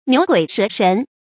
成語注音 ㄋㄧㄨˊ ㄍㄨㄟˇ ㄕㄜˊ ㄕㄣˊ
成語拼音 niú guǐ shé shén
牛鬼蛇神發音